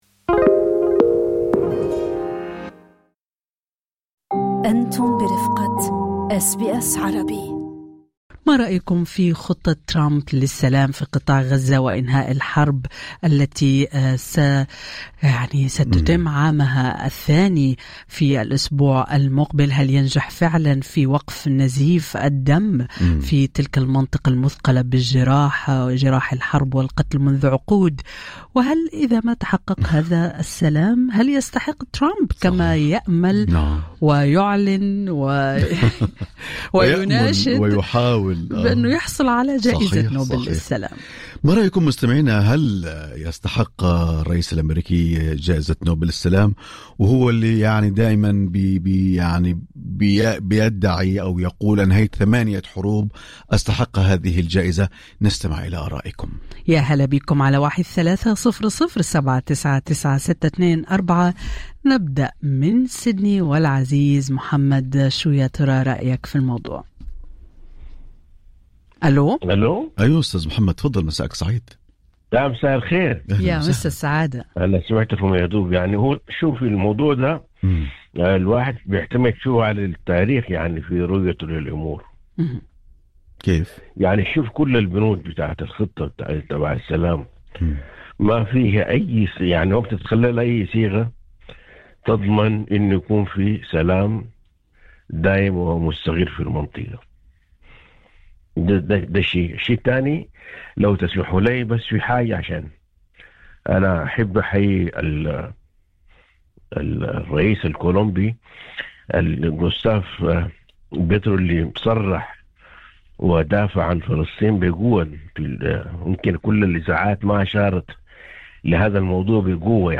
ناقش برنامج “أستراليا اليوم” في الحوار المباشر مع مستمعي أس بي أس عربي، طموح الرئيس الأمريكي دونالد ترامب في الحصول على جائزة نوبل للسلام، بعد طرحه خطة لإنهاء حرب غزة التي تدخل عامها الثاني.